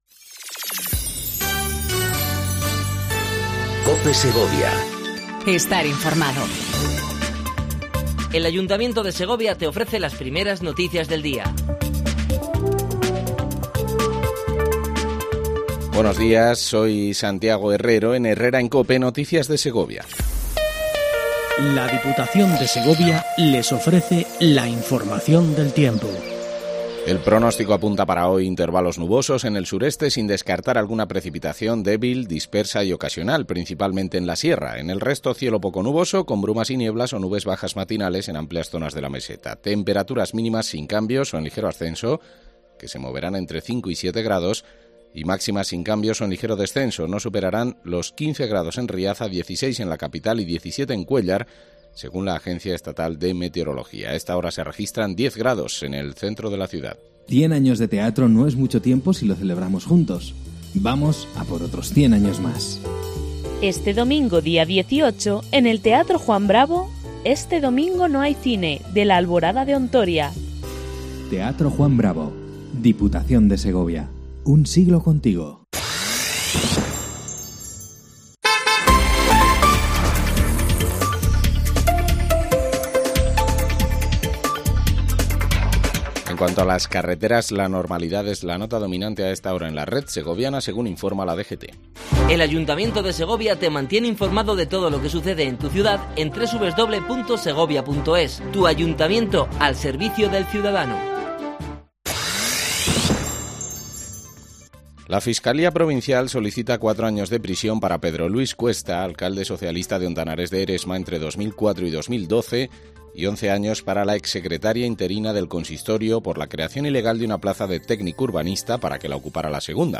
AUDIO: Primer informativo local en cope segovia